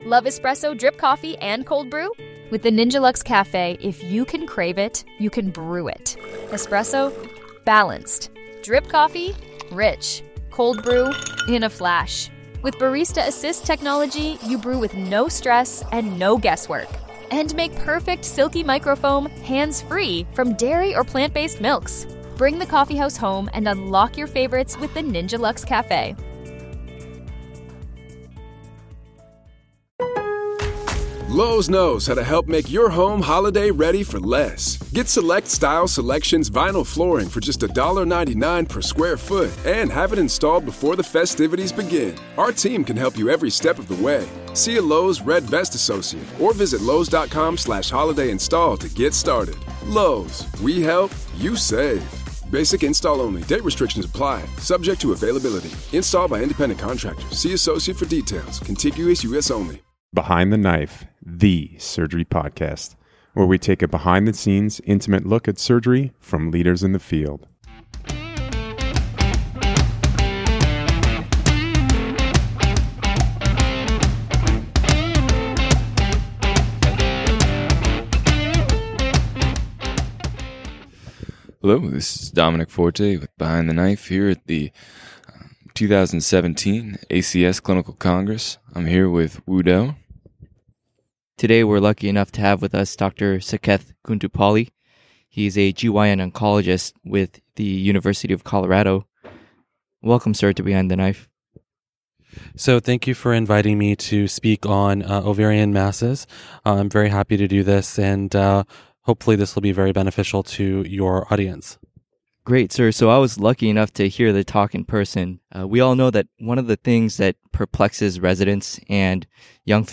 Behind the Knife coverage of the 2017 American College of Surgeons Clinical Congress